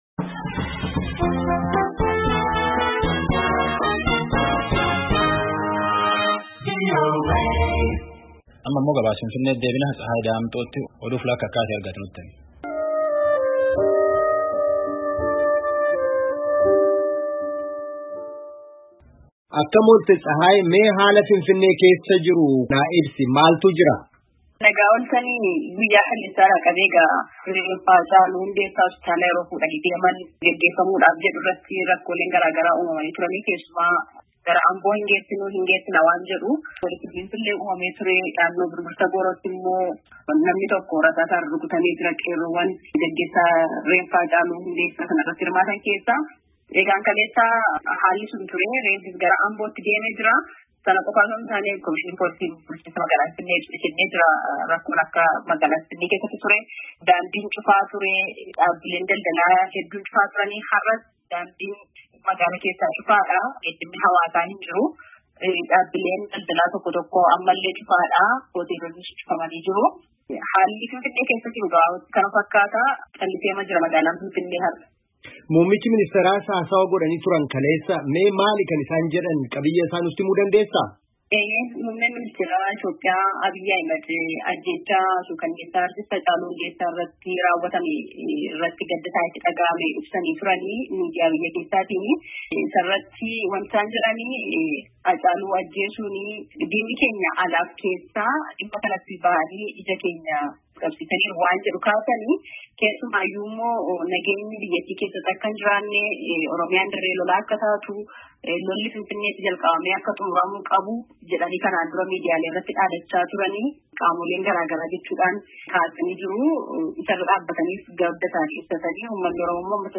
FINFINNEE, WAASHINGTON DIISII —